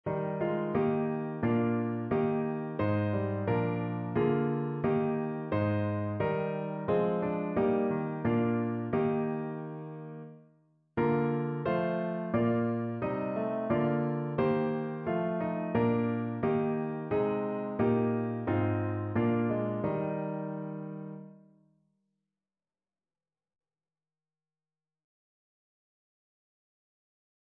Notensatz 1 (4 Stimmen gemischt)
• gemischter Chor mit Akk. [MP3] 269 KB Download